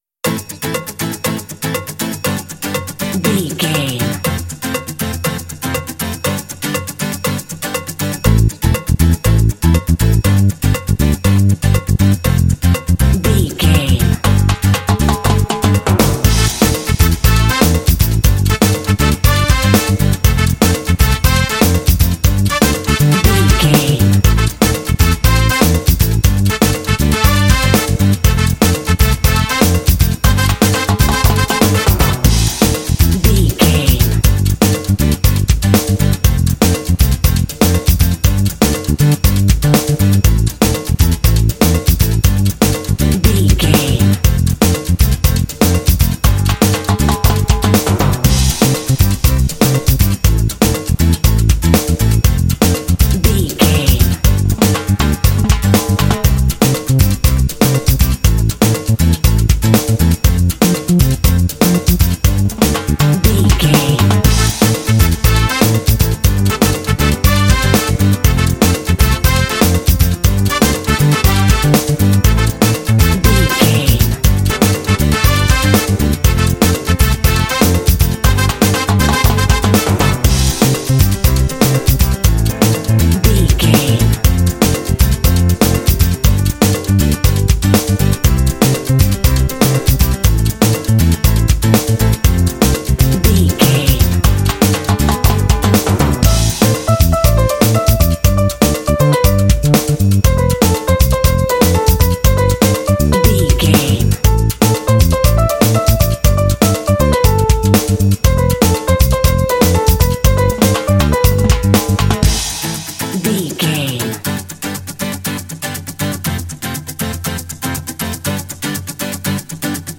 Uplifting
Aeolian/Minor
smooth
lively
energetic
driving
percussion
acoustic guitar
bass guitar
drums
brass
piano
latin